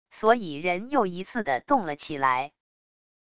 所以人又一次的动了起来wav音频生成系统WAV Audio Player